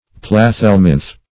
Plathelminth \Plat*hel"minth\, n.